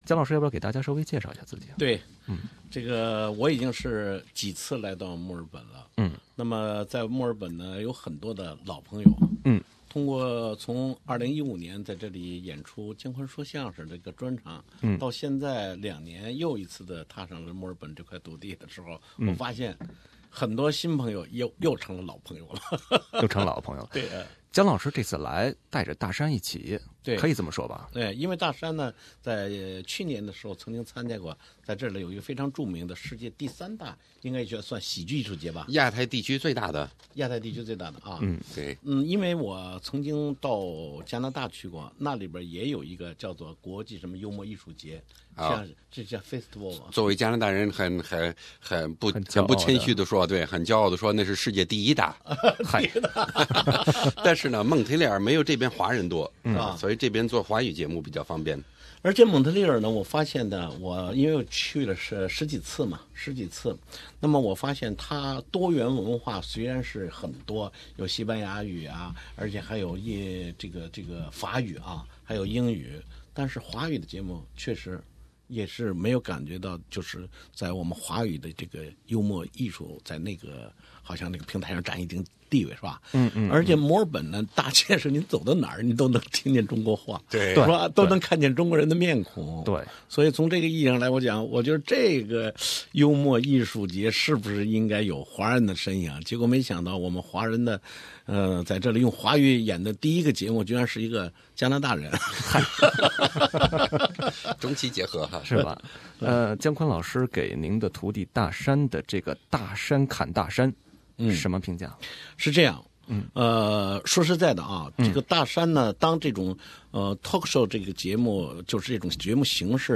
【专访】打破喜剧的文化壁垒——姜昆、大山